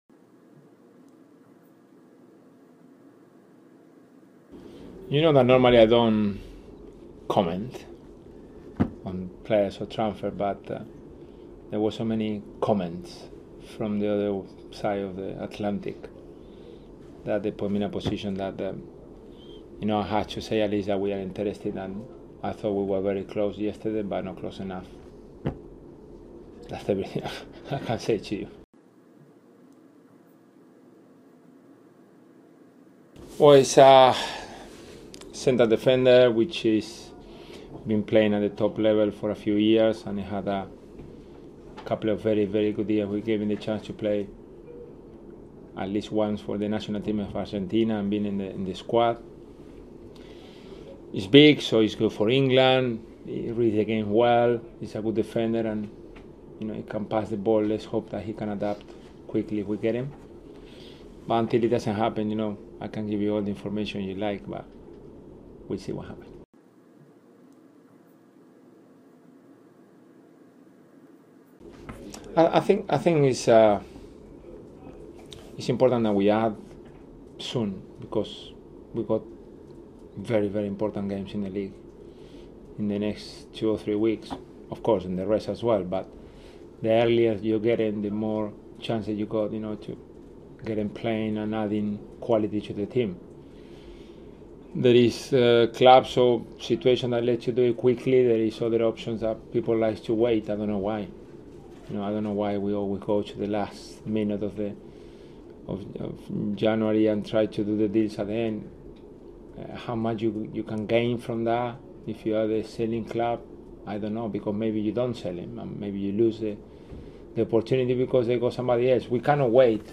Gus Poyet is quzzed by the media ahead of Saturday's clash with fellow strugglers Fulham. Poyet unusually, discuss' some recent transfer activity and comments of the future of Cabral.